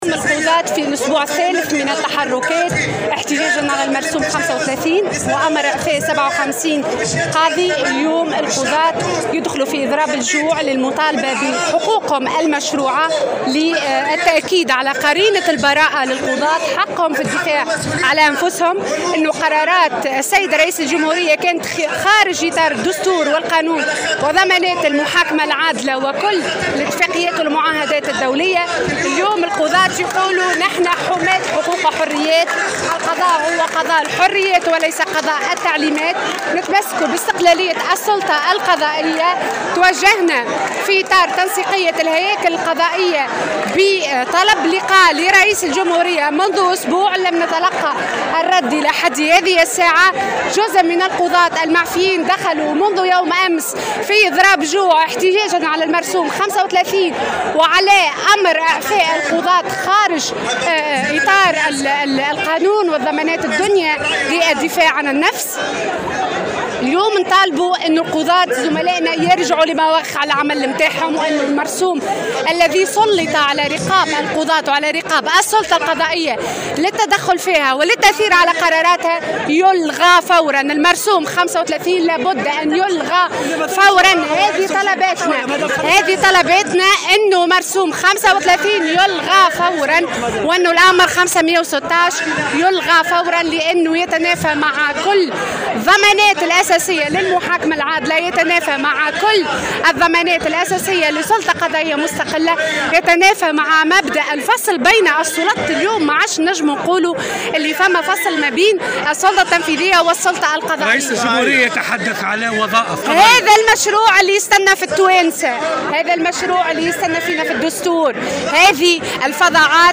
في تصريح لمبعوث الجوهرة أف أم إلى الوقفة الاحتجاجية للقضاة في إطار "يوم غضب القضاة" أمام قصر العدالة بالعاصمة